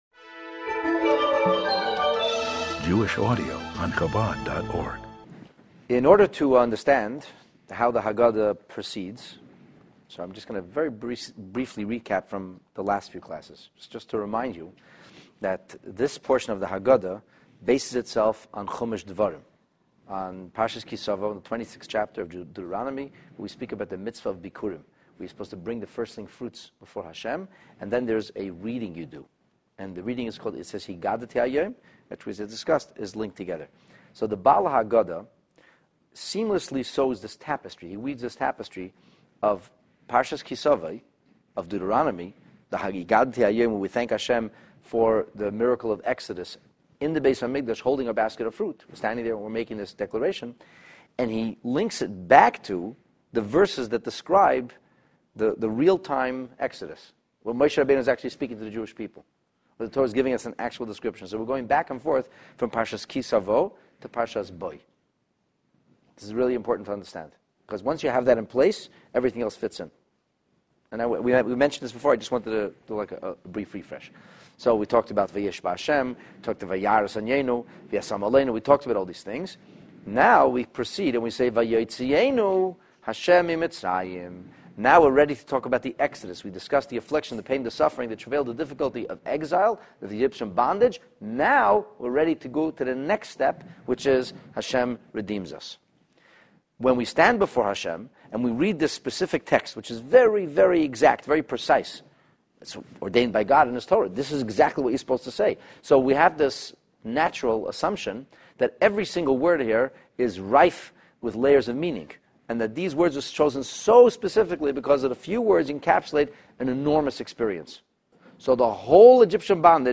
The Haggadah in Depth Part 12 This class explores the cryptic section of the Haggadah that places great emphasis on G-d “Himself” taking us out of Egypt. You’ll discover why it was so important to remove the participation of any mediums from the actual redemption process, and garner newfound understanding and appreciation of why this had to be left in the ‘hands’ of the Creator alone.